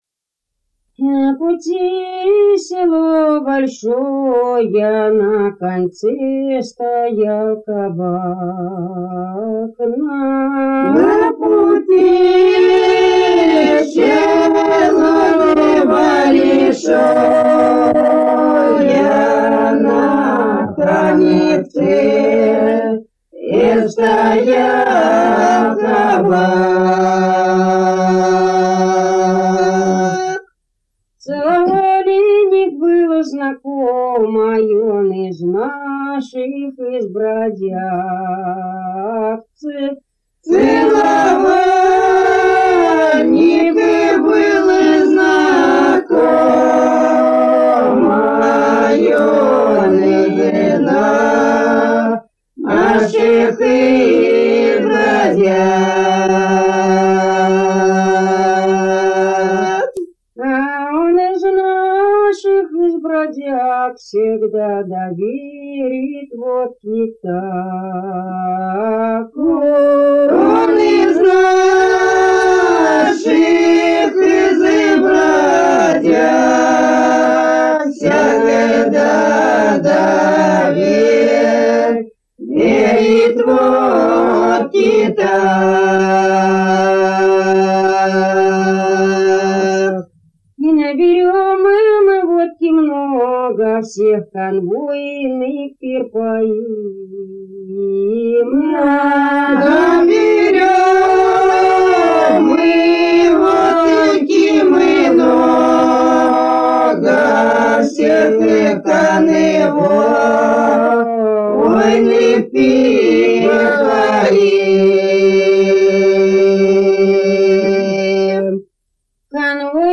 Народные песни Касимовского района Рязанской области «На пути село большая», разбойничья.